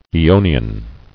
[ae·o·ni·an]